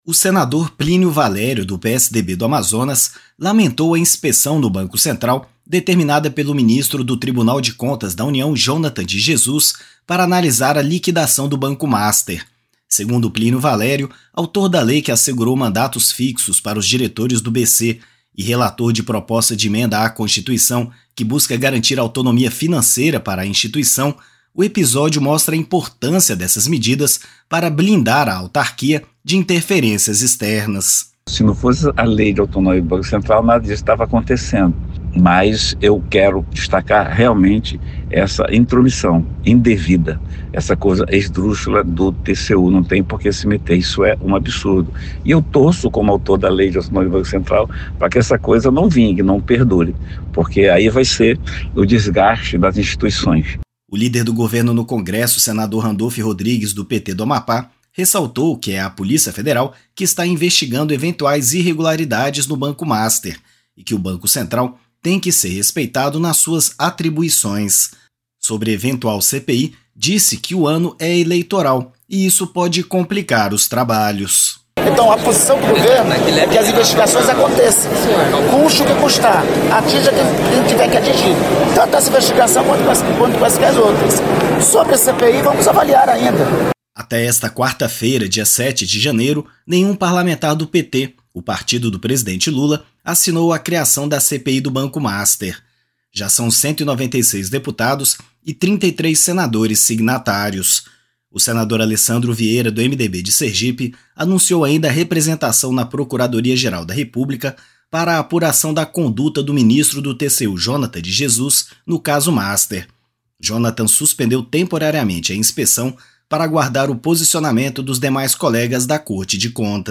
Senadores comentam tentativa de interferência no Banco Central no episódio do banco Master